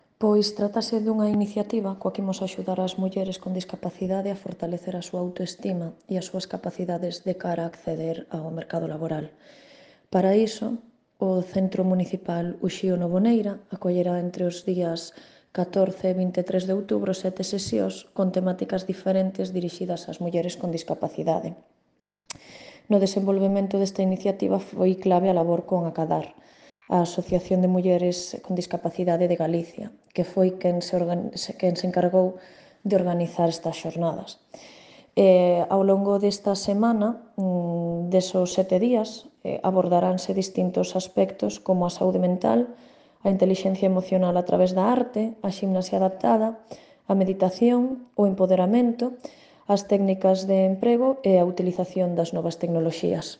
A concelleira de Muller, Ángeles Novo, fala da importancia d